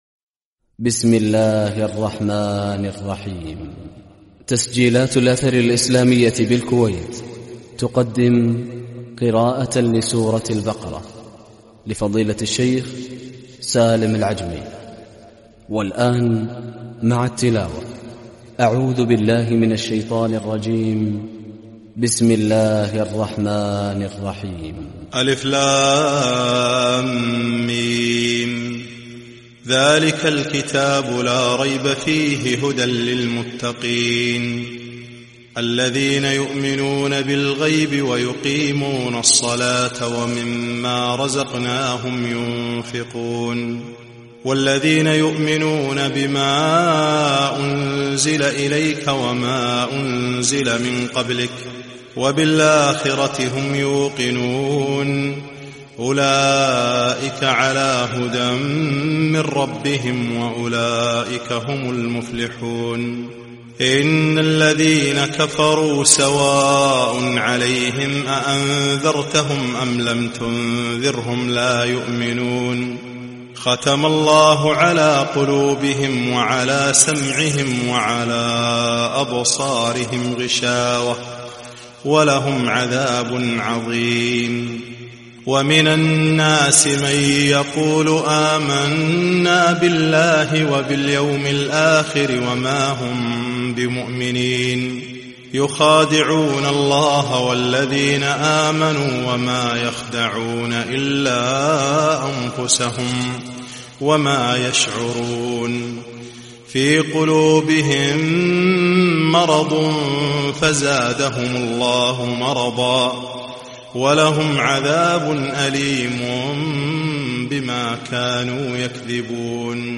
سورة البقرة - تلاوة